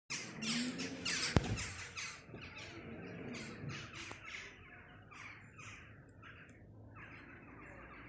Kovārnis, Corvus monedula
Skaits20 - 30
StatussDzied ligzdošanai piemērotā biotopā (D)
PiezīmesUz mājas jumta uzturas kovārņu bars, sasaucas, lido pāri pagalmam